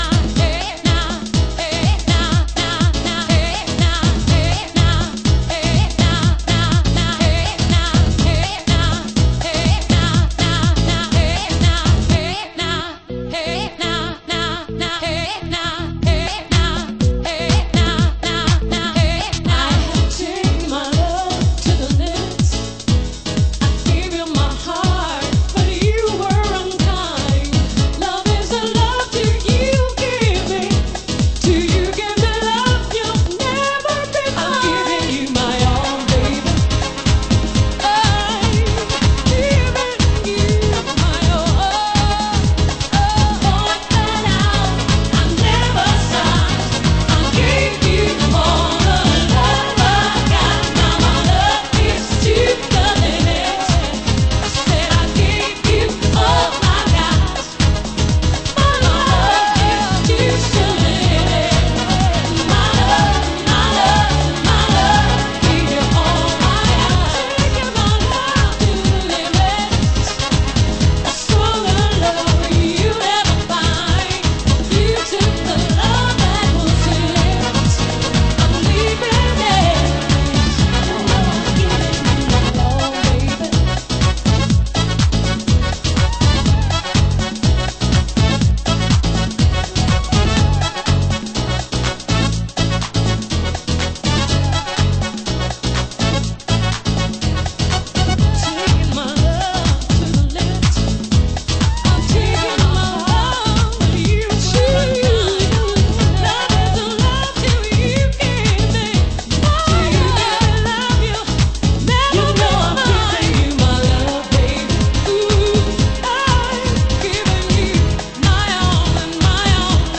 Extended Vocal Mix
盤質：A1/B1のイントロに小傷によるプチノイズ有（どちらも試聴箇所になっています）